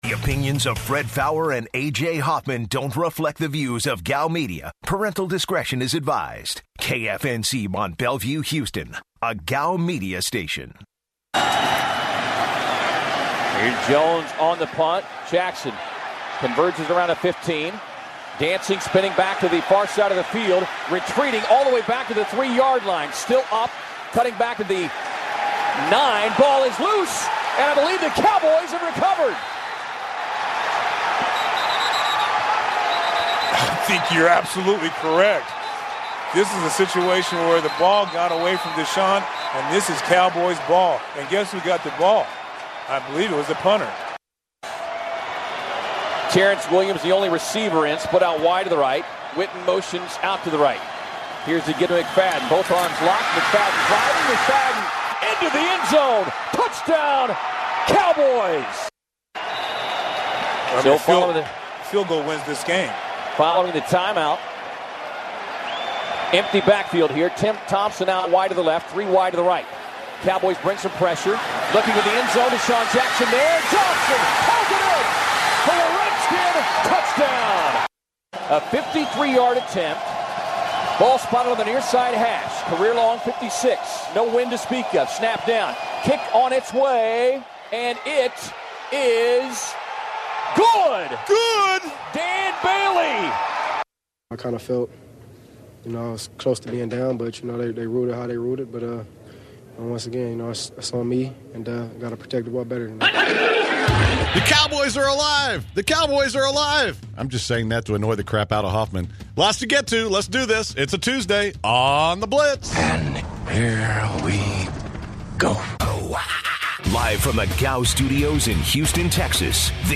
To open the show, the guys react to MNF!Plus, they share their thoughts on potential moves in the MLB off season. Finally, to close the hour, the guys interview Herman Moore- former lions wide receiver- to talk about the Quick Lane Bowl!